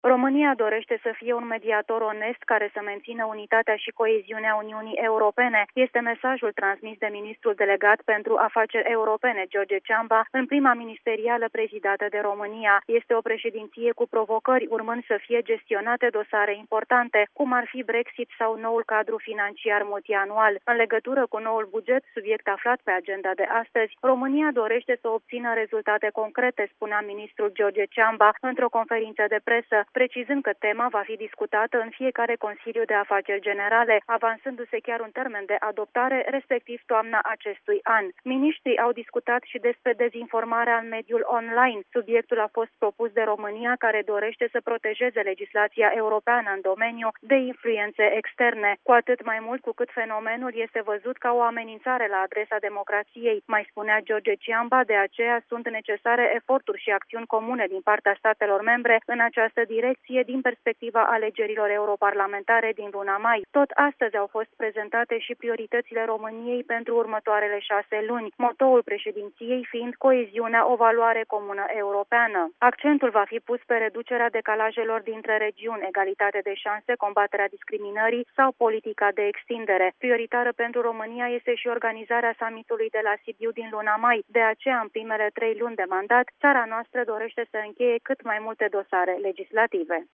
România promite să fie un mediator onest pe timpul mandatului său la preşedinţia Consiliului de miniştri al Uniunii Europene. Declaraţiile au fost făcute la Bruxelles de ministrul pentru afaceri europene, George Ciamba, care a condus reuniunea cu omologii săi din statele Uniunii, prima reuniune prezidată de România de la preluarea mandatului.